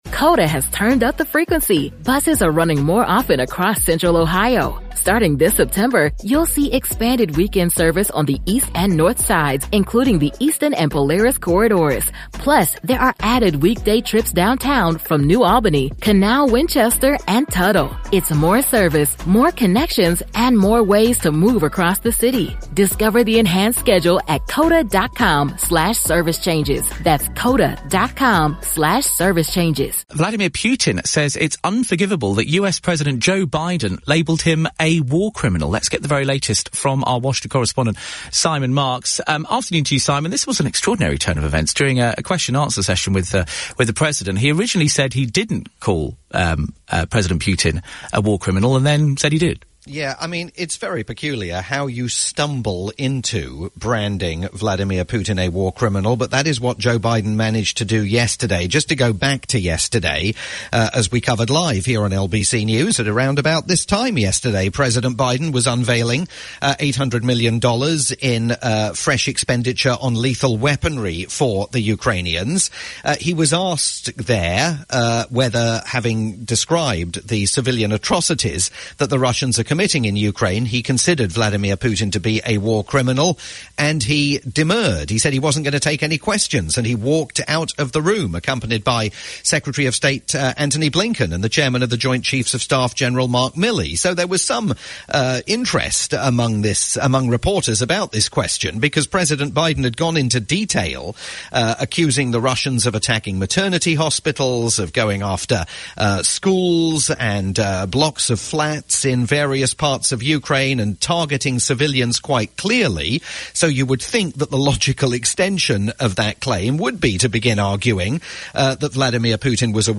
live report for LBC News